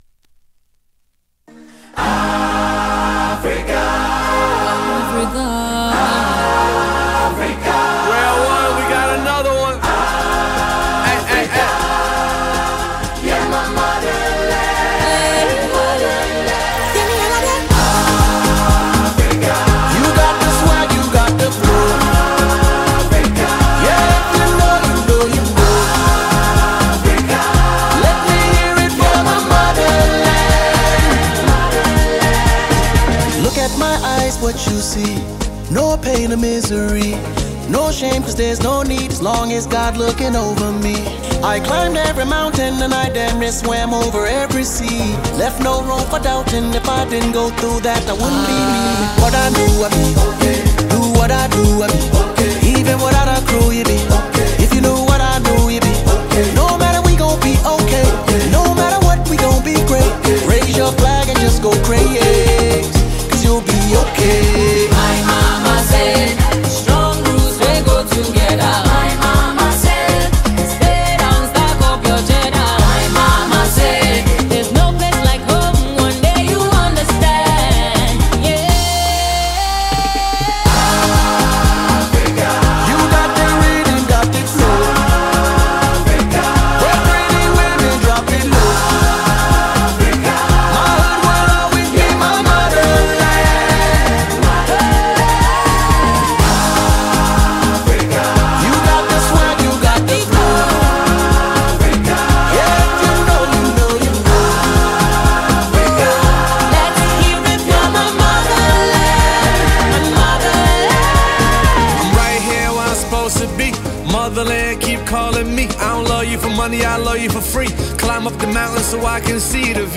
energetic and soulful vocals